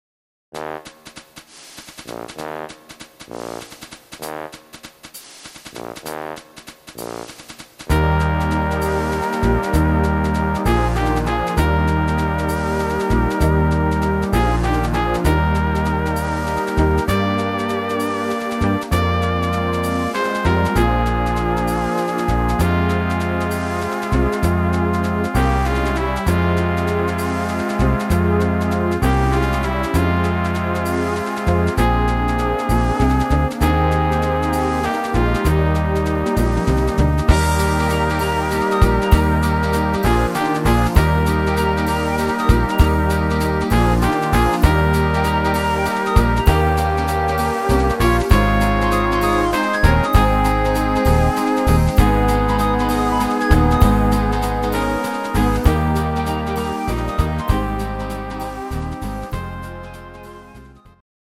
instr. Posaune